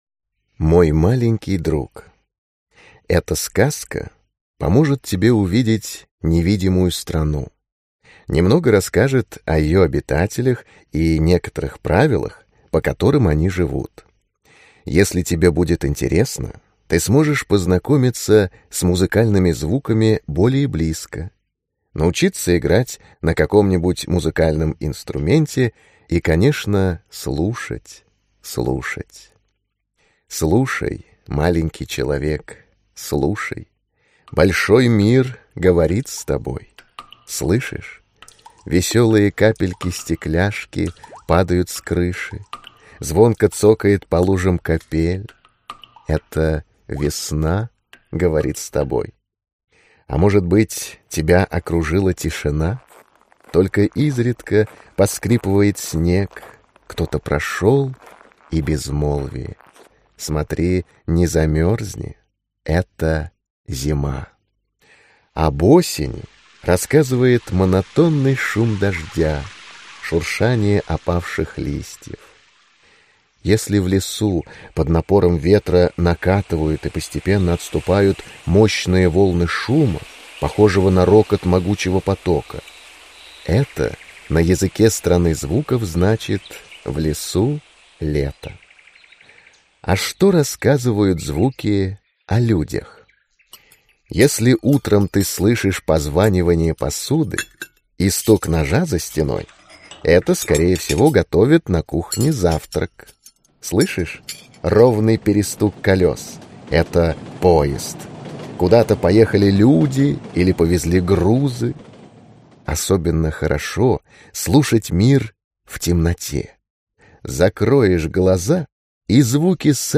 Аудиокнига Волшебный мир музыкальных звуков | Библиотека аудиокниг